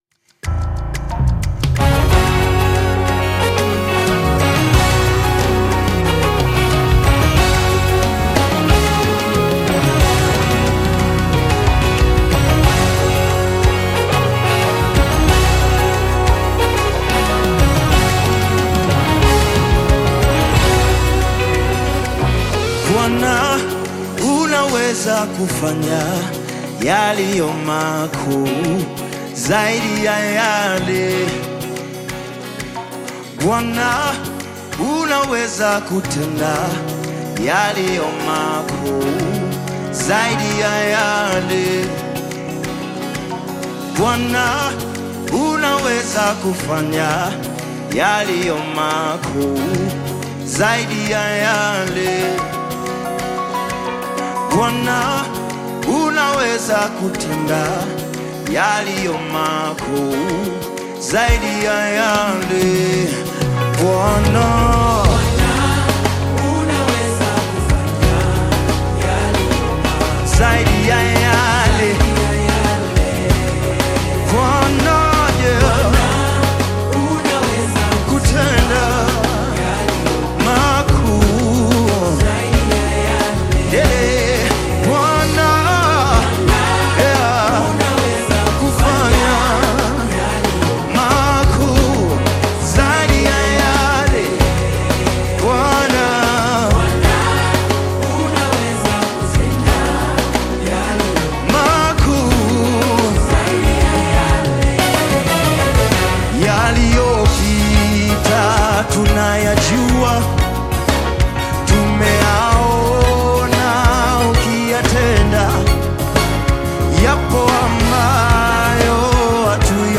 Tanzanian Gospel artist, singer and songwriter
Gospel song
You can also discover more Nyimbo za Dini music below.